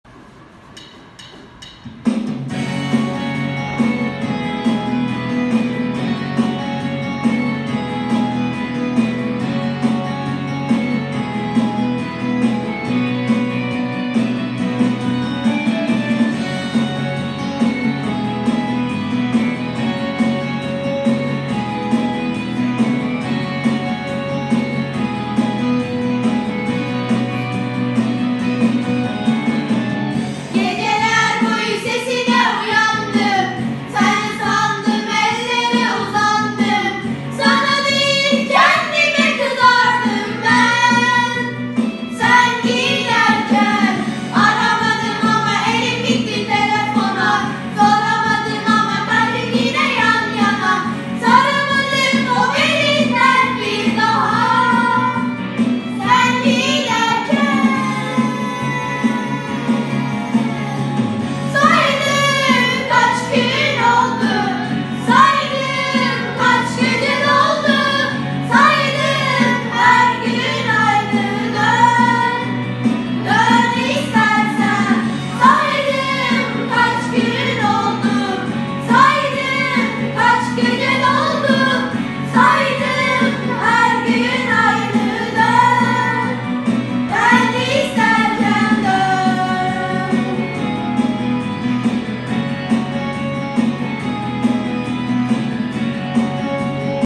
Giho Şarkı Yarışması Albümü